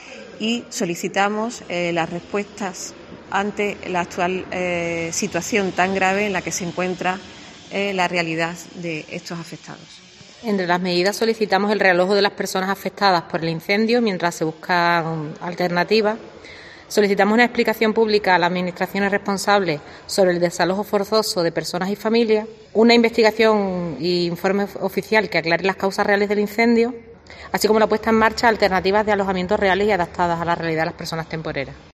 Portavoces de distintas ONG's